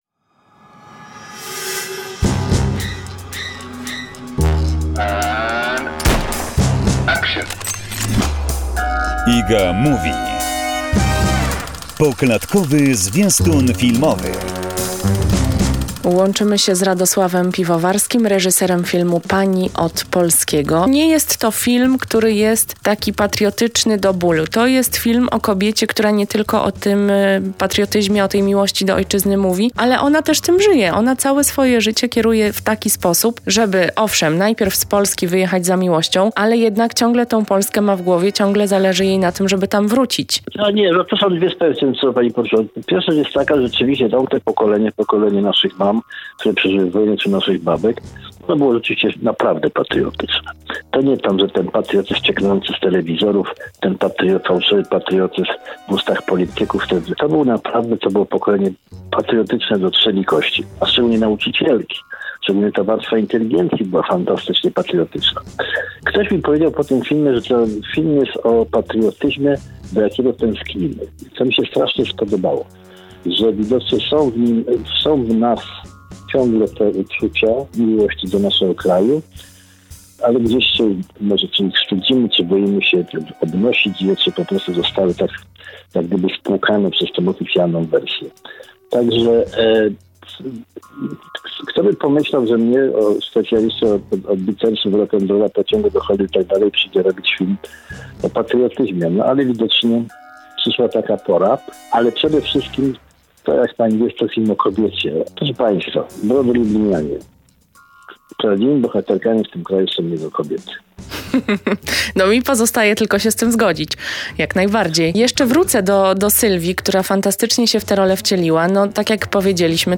rozmowa z Radosławem Piwowarskim cz. 4